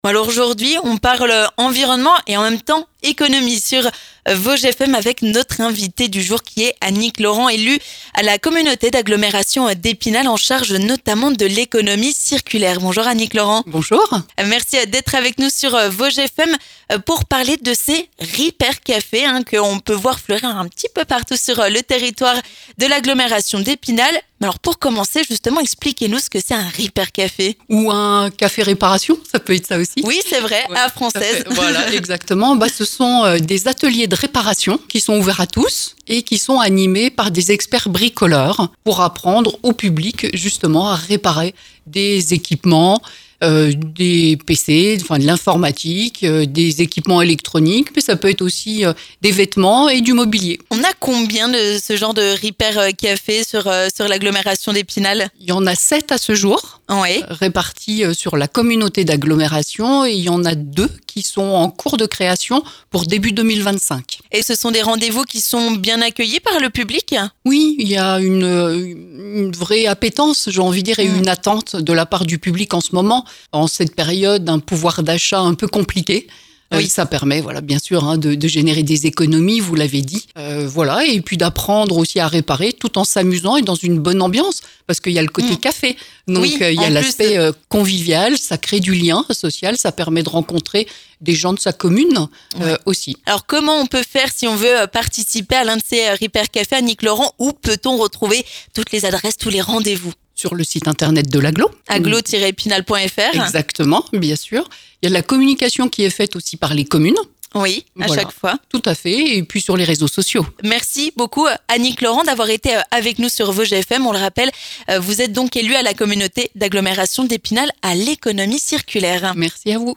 On en parle avec Annick Laurent élue à la CAE, déléguée à l'économie circulaire.